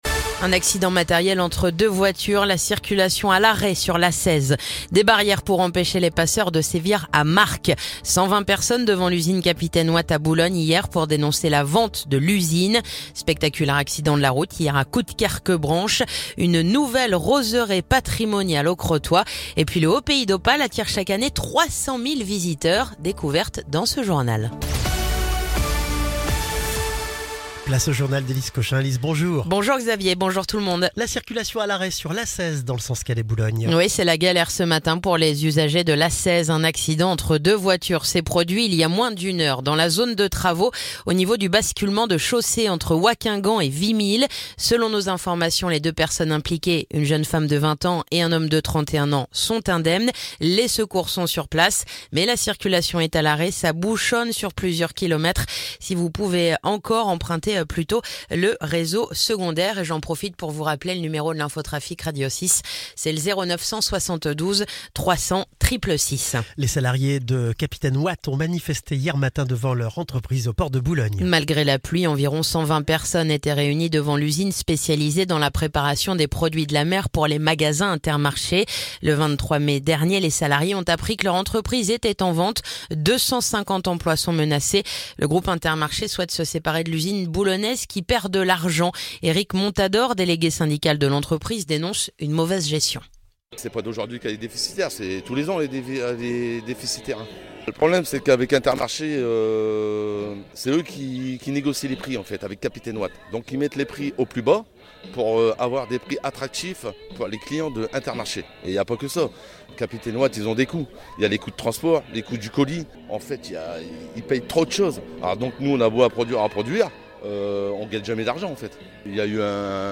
Le journal du vendredi 6 juin